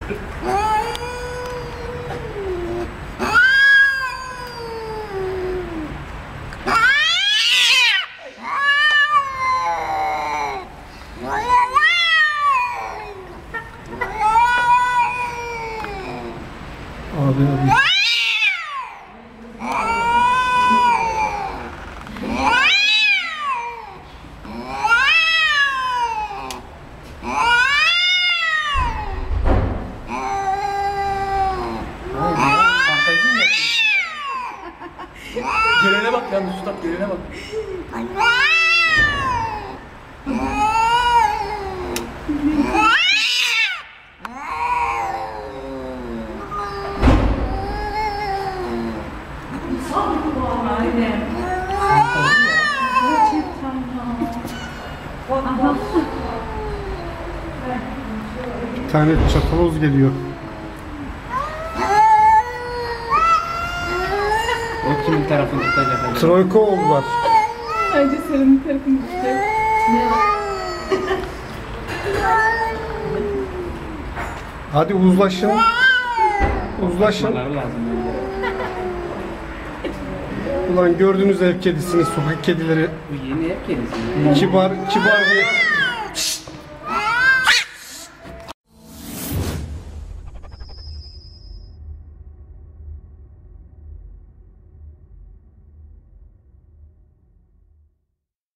دانلود صدای گربه های عصبانی در حال دعوا از ساعد نیوز با لینک مستقیم و کیفیت بالا
جلوه های صوتی